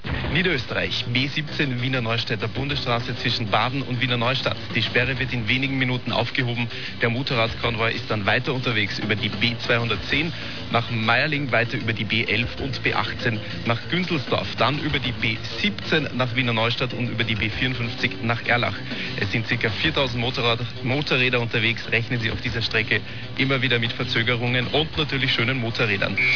Der Radiosender �3 unterst�tzte die Toyrun durch laufende Verkehrsdurchsagen, hier ein Livemitschnitt einer solchen Verkehrsfunkdurchsage.
verkehrsdurchsage.mp3